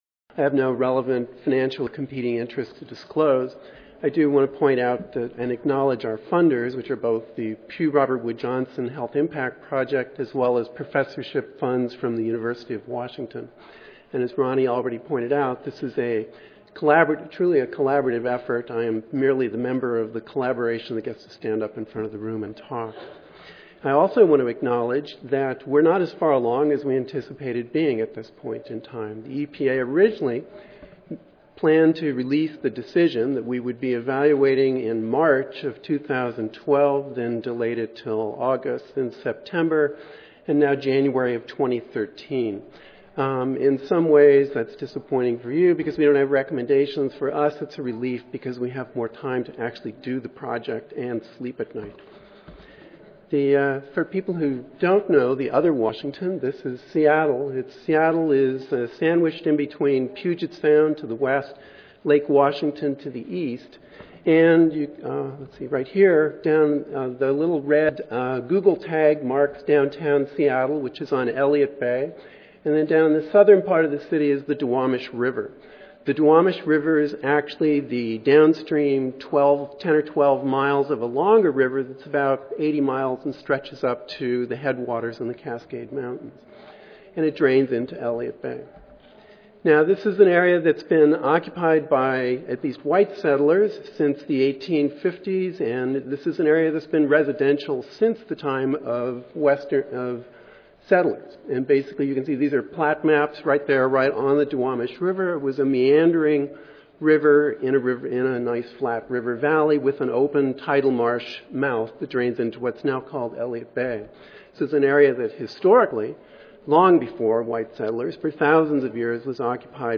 In this coordinated session, speakers will describe methods and findings from five HIAs addressing local public health concerns. They will also discuss strengths and limitations of the method, and describe some of their experiences with the process of conducting and using HIA.